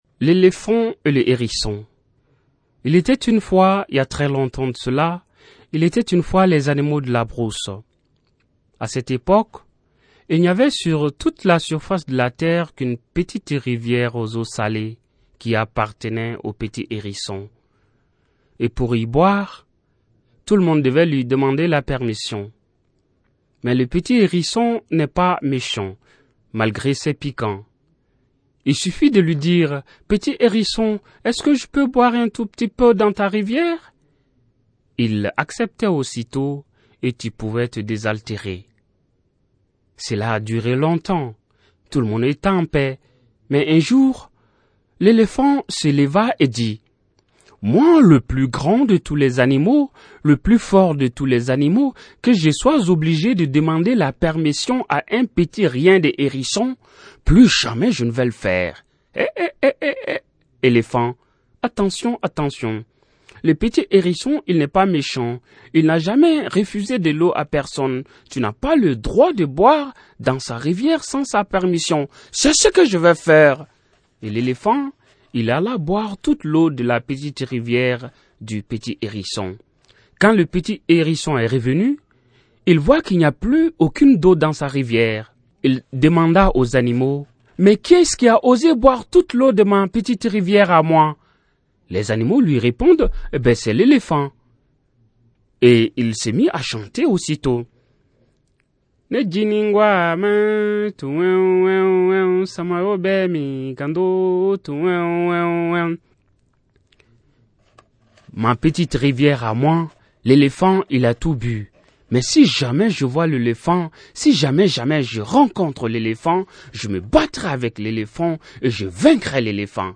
Origine de la collecte : Mali